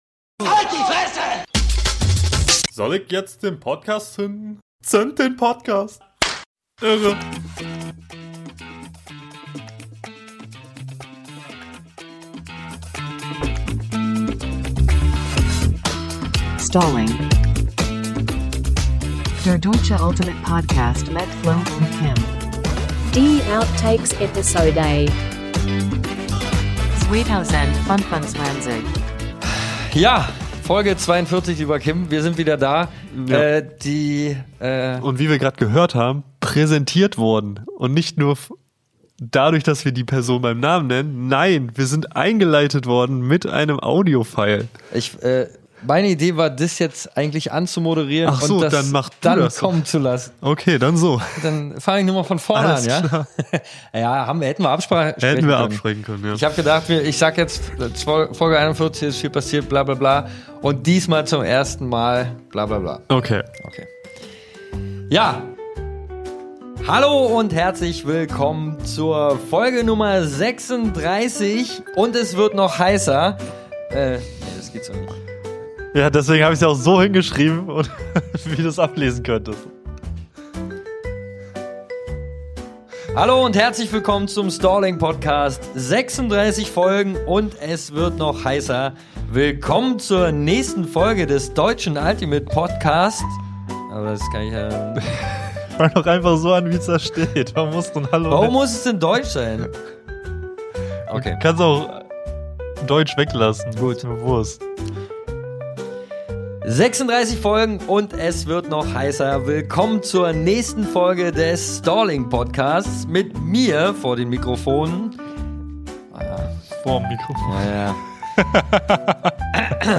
Auch diese Jahr haben wir wieder fleißig Outtakes produziert und was ihr gerade hört ist das Ergebnis. Es ist keine vollwertige Folge, dient aber hoffentlich trotzdem zur allgemeinen Erheiterung.